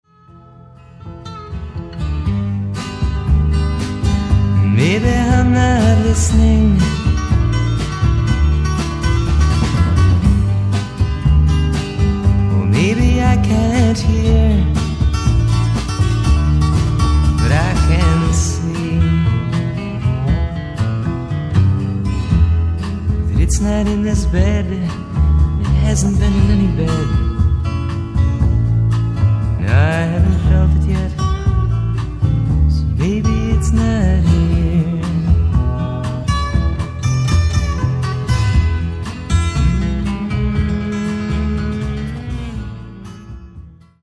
ジャズ、カントリー、ソウルなどを絶妙にブレンドして作られたサウンドはヒップかつクール、とにかく洗練されている。